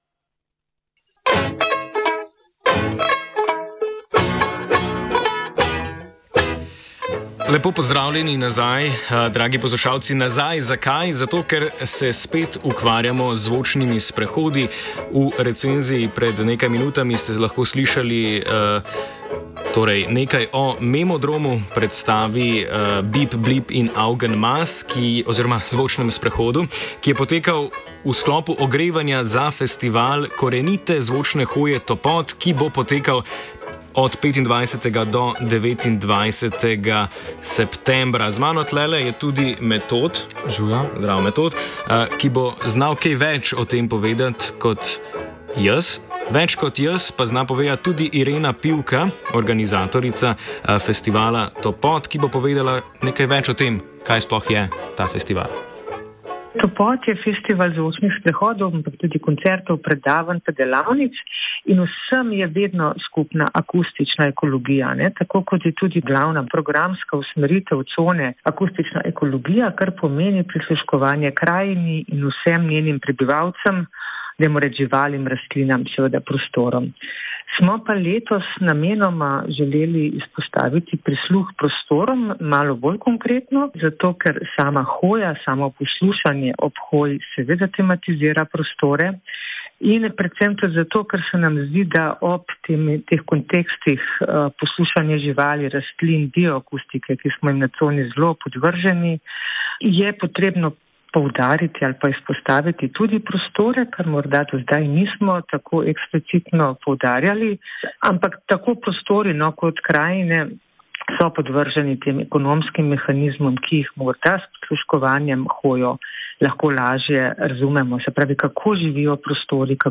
pogovor-o-festivalu.mp3